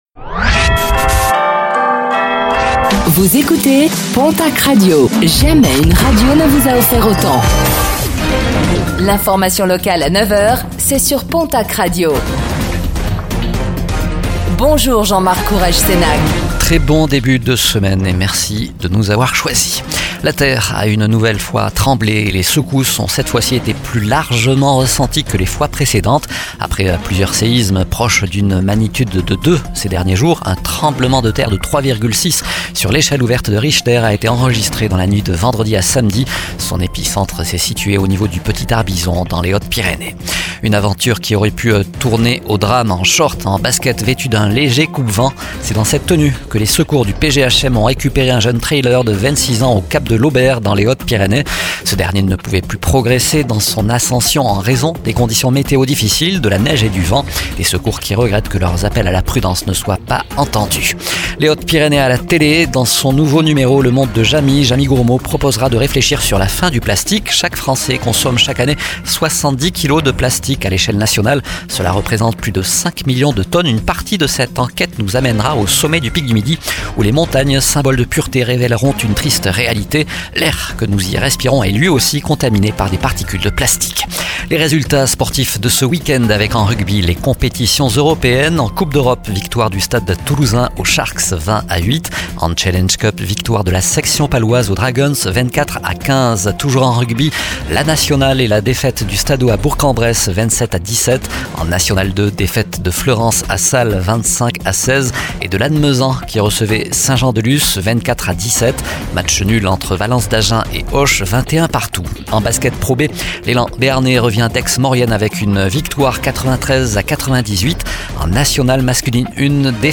Infos | Lundi 13 janvier 2025